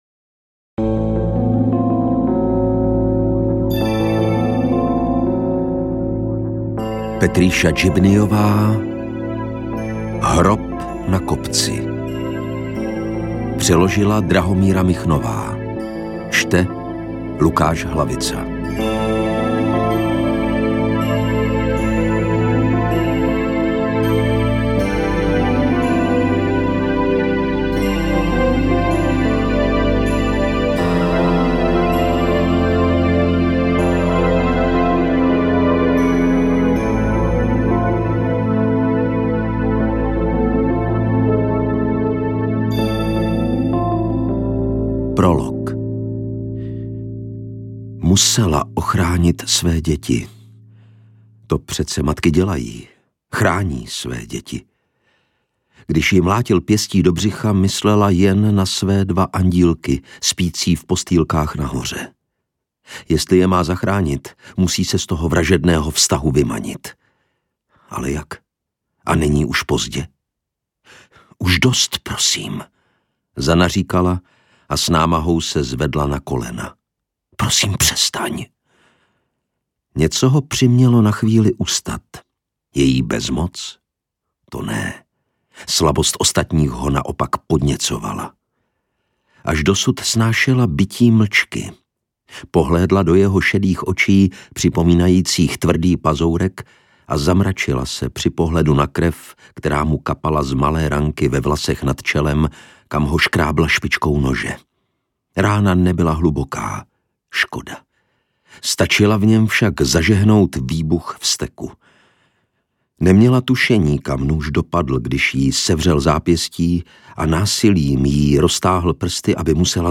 Interpret:  Lukáš Hlavica
AudioKniha ke stažení, 79 x mp3, délka 15 hod. 18 min., velikost 1088,7 MB, česky